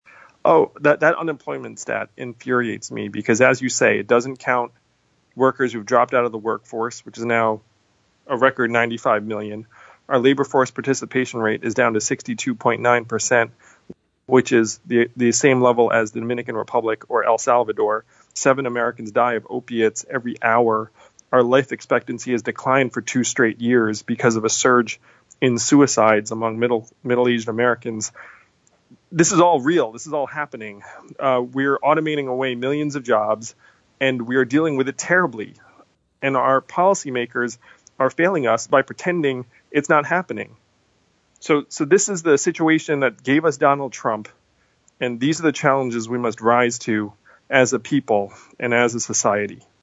In-Depth Interview: 2020 Presidential Candidate Andrew Yang Warns That Robots and AI Will Displace Millions of American Workers